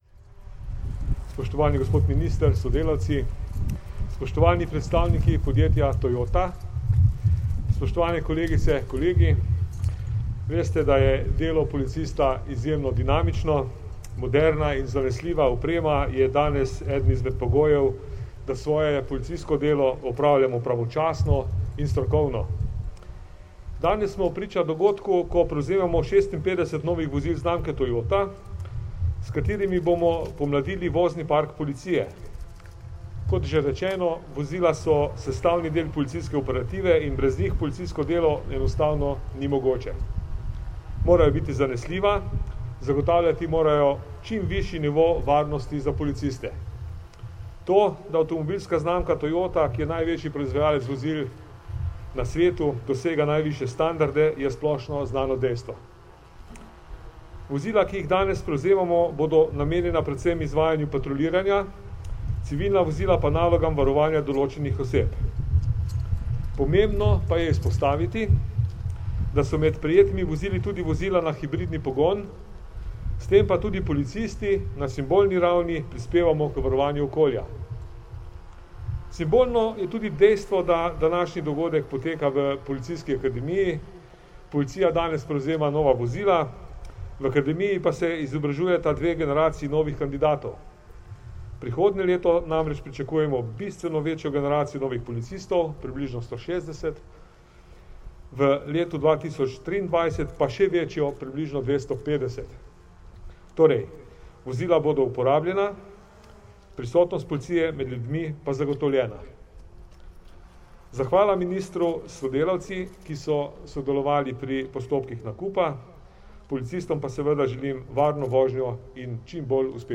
Namestnik generalnega direktorja policije Danijel Žibret in minister za notranje zadeve Aleš Hojs policistom sta danes, 16. novembra 2021, v Policijski akademiji v Ljubljani policistom predala nova službena vozila.
Zvočni posnetek izjave Danijela Žibreta